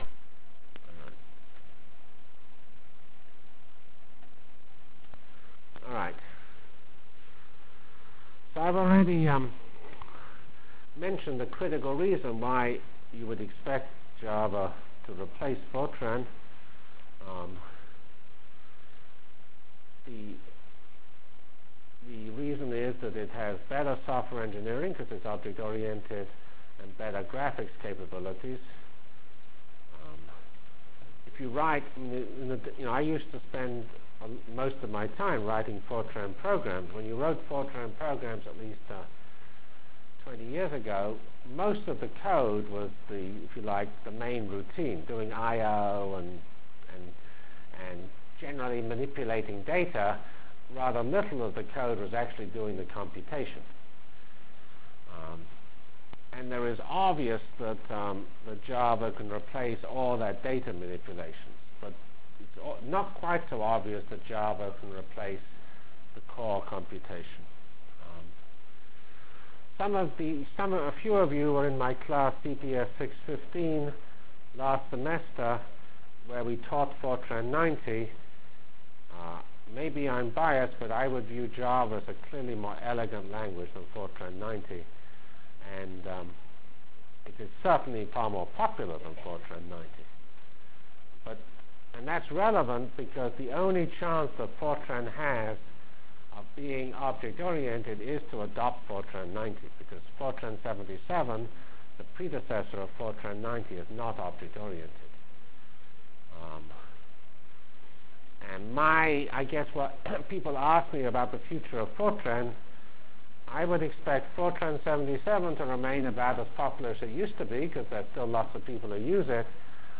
From Feb 5 Delivered Lecture for Course CPS616